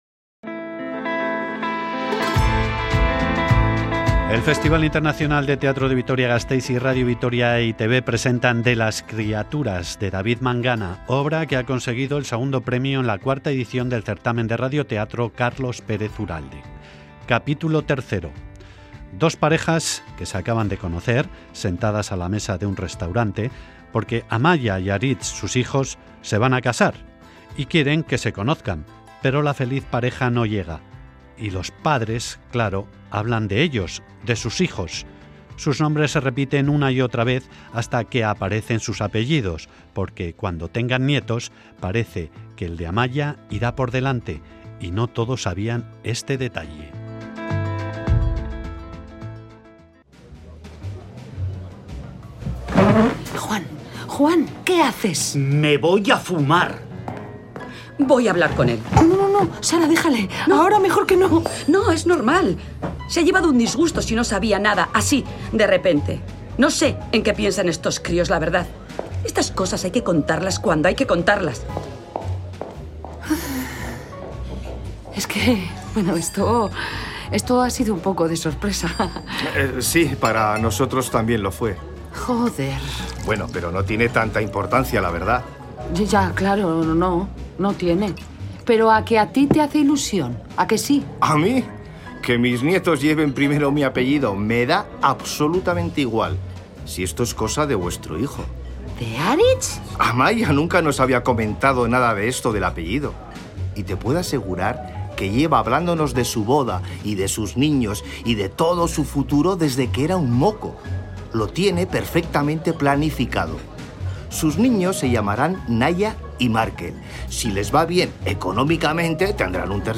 Grabado en Sonora Estudios.